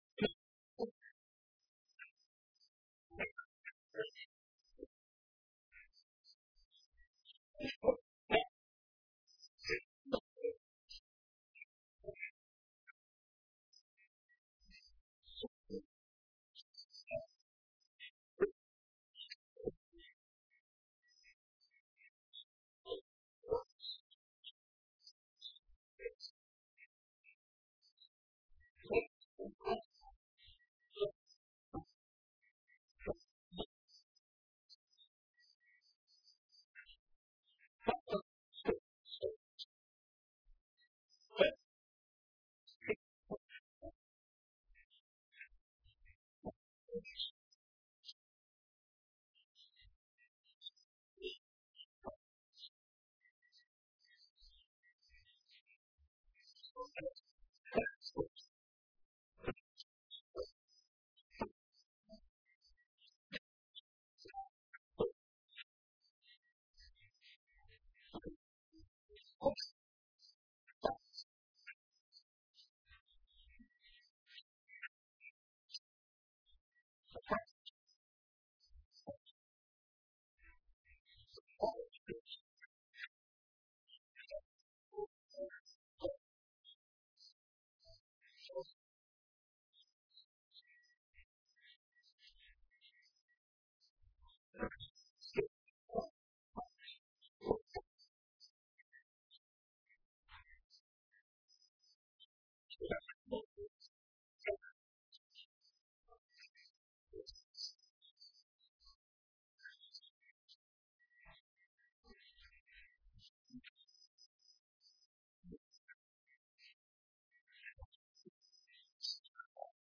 Online Sermons at St. Pauls